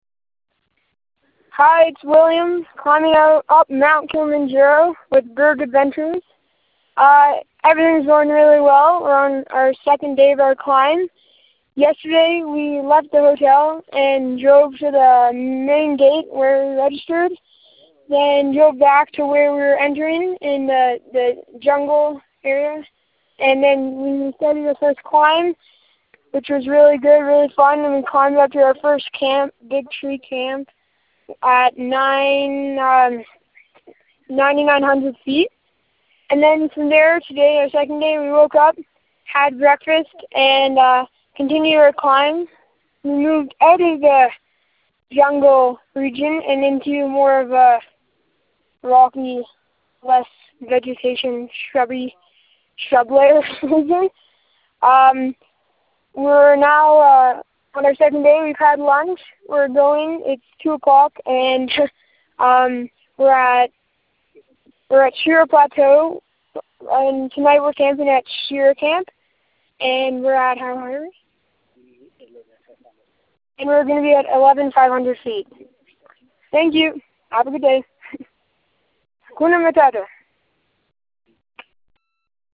Calls in on the Way to Shira Camp